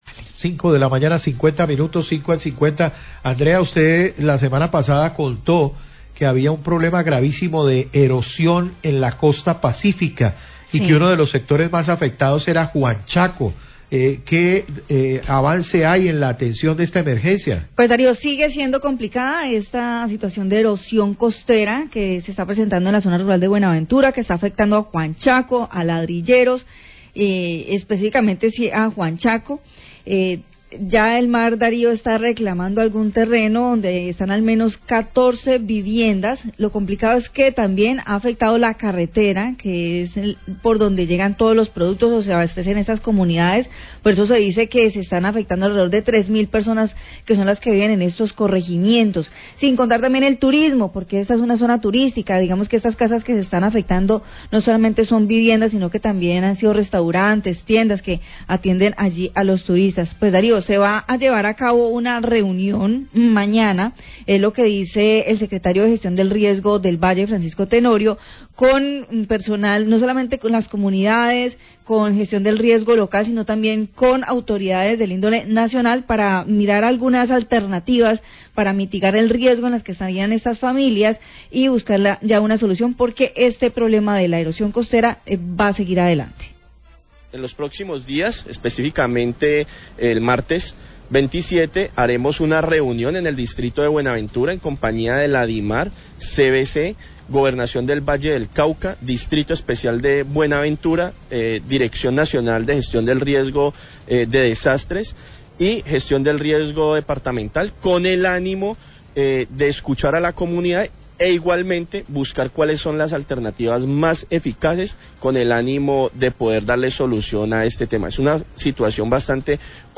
Radio
El Secretario de Gestión del Riesgo de Valle, Francisco Tenorio, habla de la reunión con la comunidad, el gobienro nacional, la CVC, enter otras institucionaes, para evaluar al erosión costera que se presenta en Juanchaco y que deja afectaciones a cerca de 3 mil personas y a la carretera que atravieza el cgto.